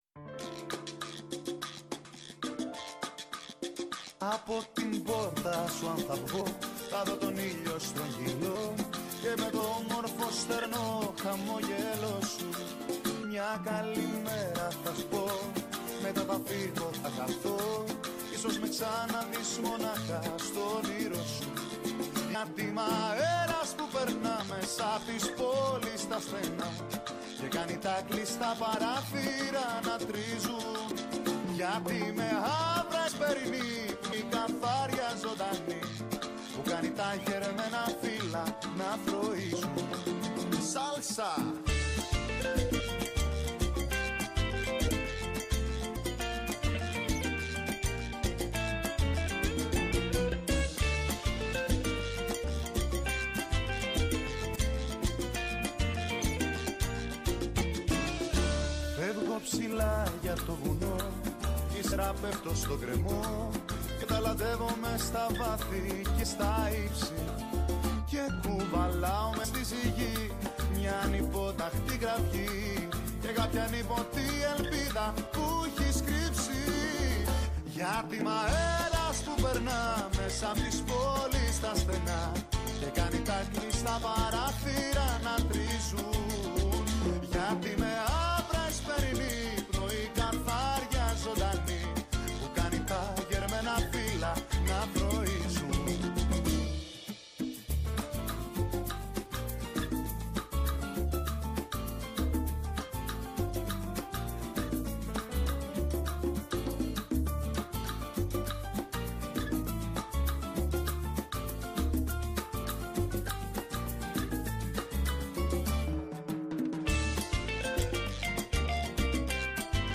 Και Ναι μεν η ματιά μας στρέφεται στην εγχώρια επικαιρότητα, Αλλά επειδή ο κόσμος “ο μικρός ο μέγας” -όπως το διατύπωσε ο ποιητής- είναι συχνά ο περίγυρός μας, θέλουμε να μαθαίνουμε και να εντρυφούμε στα νέα του παγκόσμιου χωριού. Έγκριτοι επιστήμονες, καθηγητές και αναλυτές μοιράζονται μαζί μας τις αναλύσεις τους και τις γνώσεις τους.